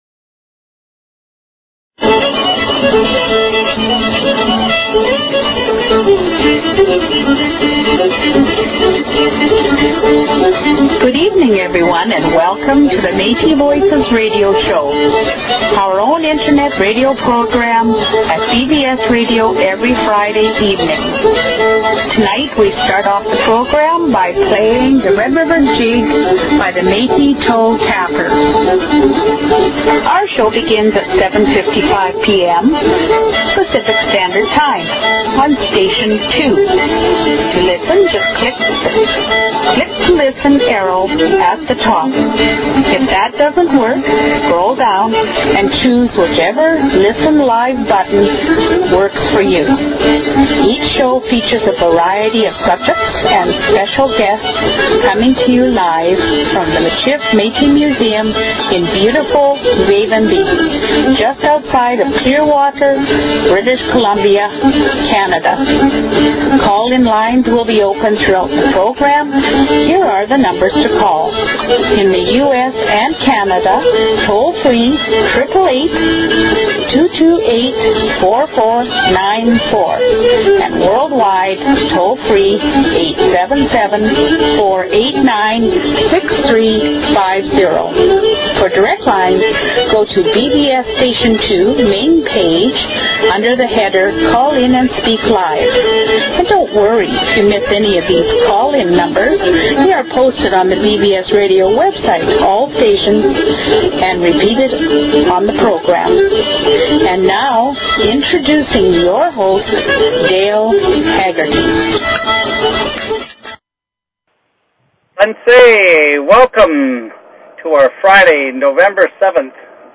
Talk Show Episode, Audio Podcast, Metis_Voices_Radio and Courtesy of BBS Radio on , show guests , about , categorized as
OPEN MIKE NIGHT!
We may have some good fiddle music throughout, as well....